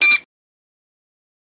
Mink Scream Short Normal Blast